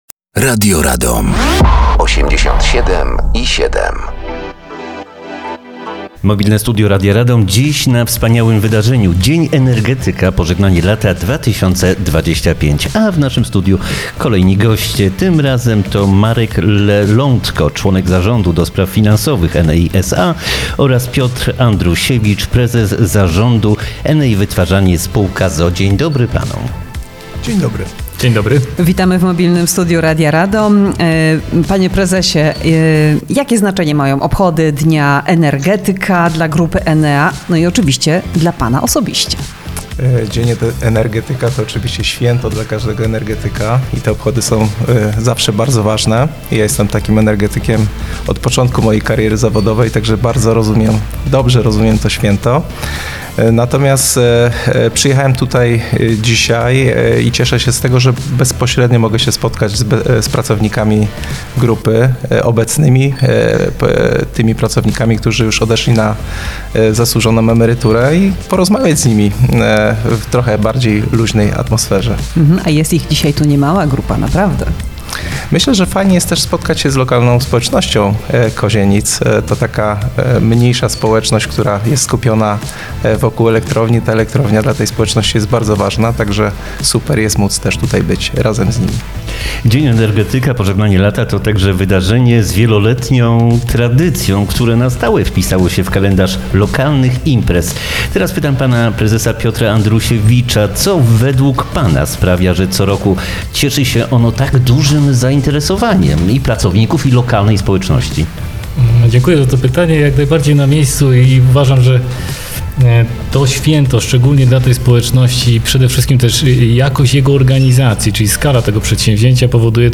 Dziś w Kozienicach wielkie święto. Cykliczna impreza Dzień Energetyka – Pożegnanie lata 2025.
Na miejscu zainstalowaliśmy Mobilne Studio Radia Radom.